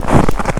High Quality Footsteps
STEPS Snow, Walk 30-dithered.wav